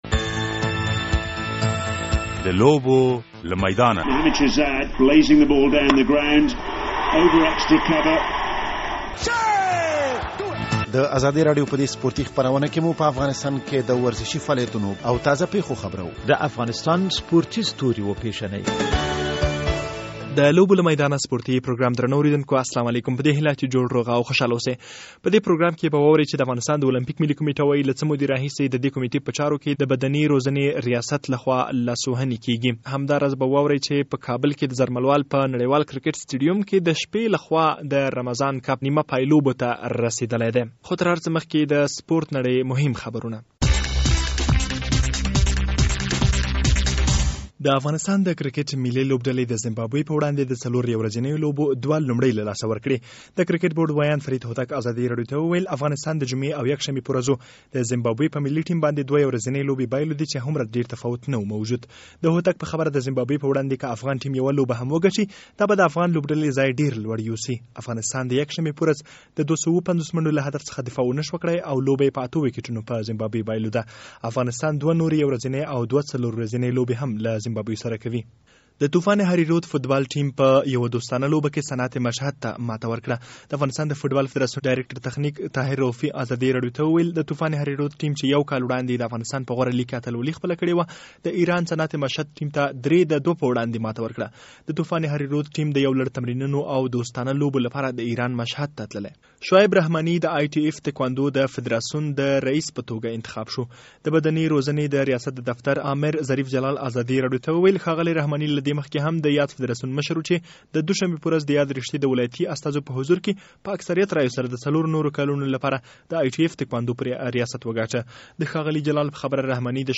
په دې پروګرام کې د کرکټ د لوبو او همدراز د المپيک او بدني روزنې د ریاست ترمنځ د لانجې په اړه راپور او مرکه خپریږي تر پایه یې واروئ.